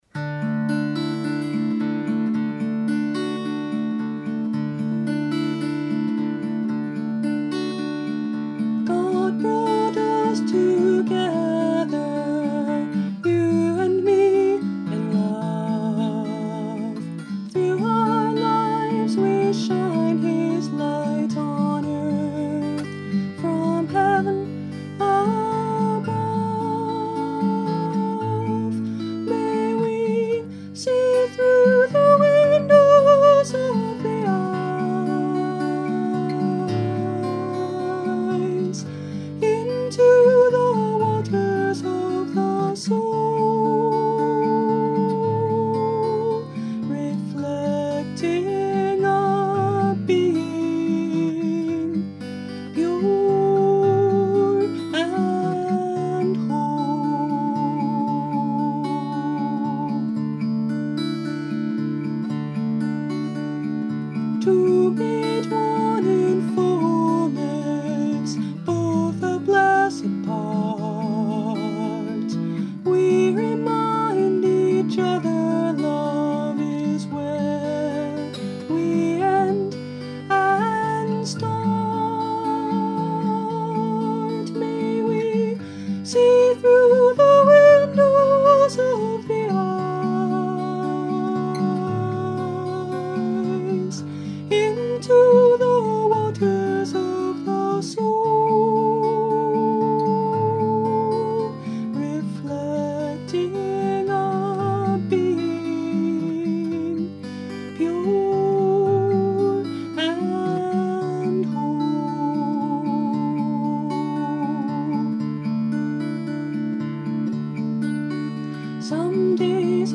Instrument: Tempo – Seagull Excursion Folk Acoustic Guitar